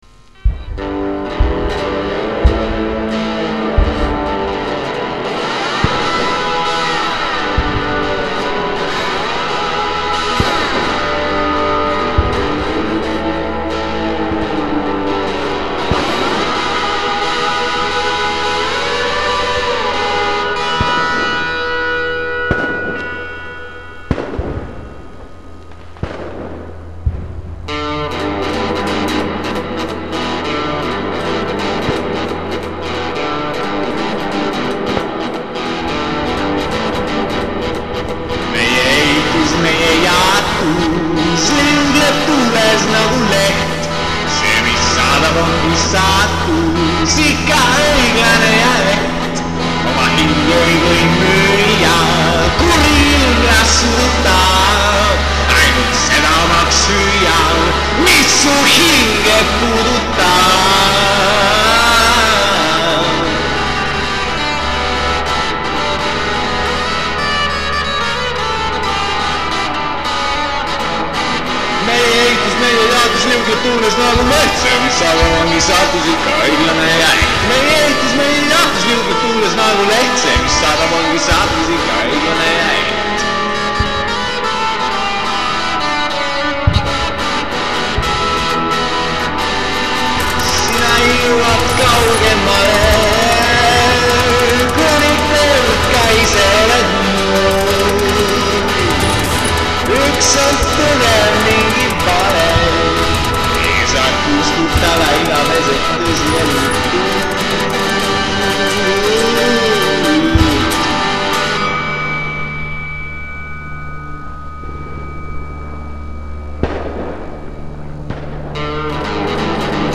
Niisiis tuli elektrikale bluesihäälestus peale kruttida ja veidi laulu lüüa.